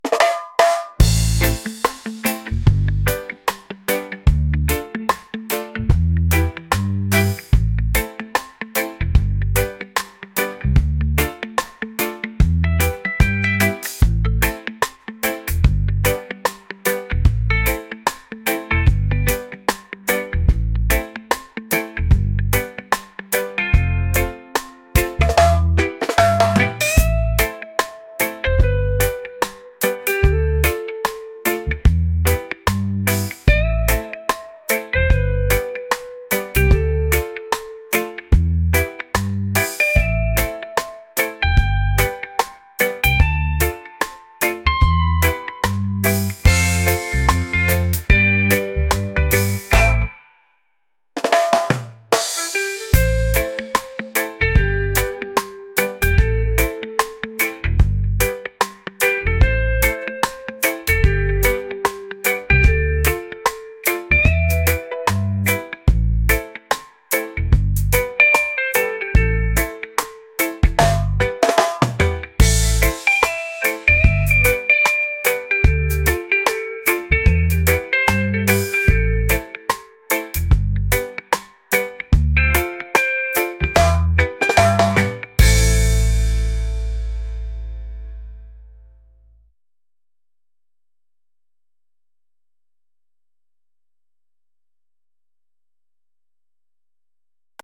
reggae | positive | laid-back